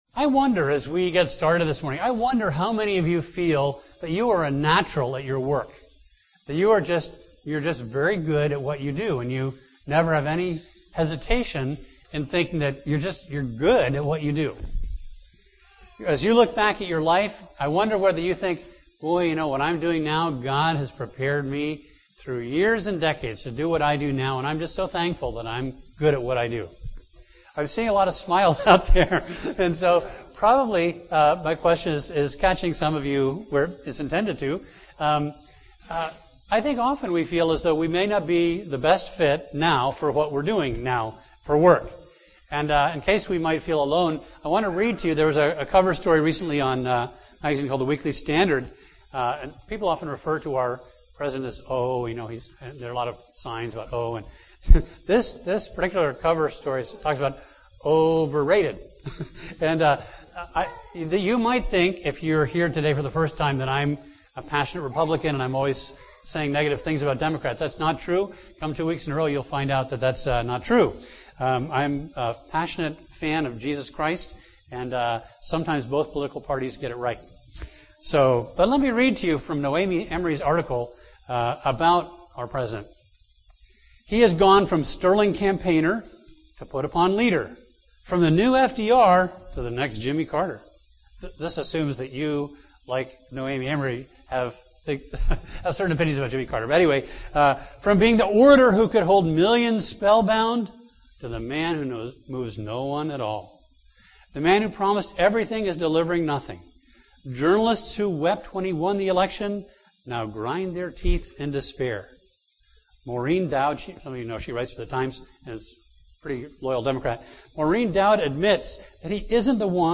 A message from the series "Heaven Help The Home."